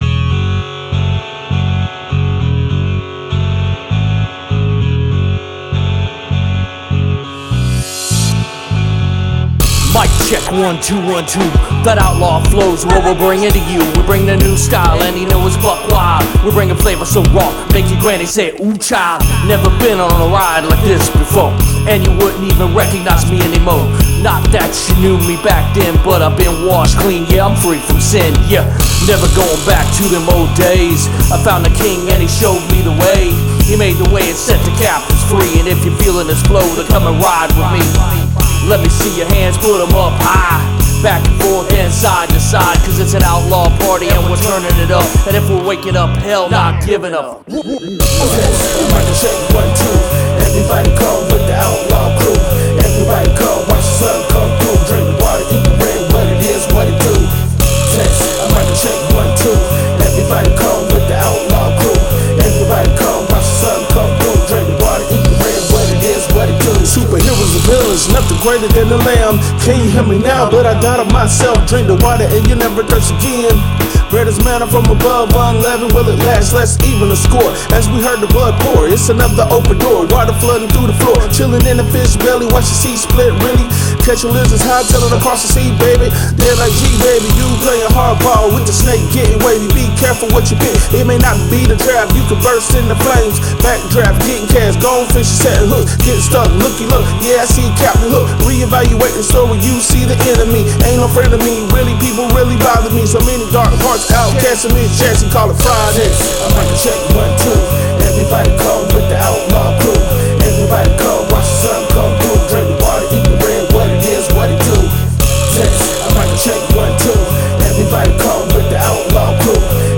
fun party anthem track to get you moving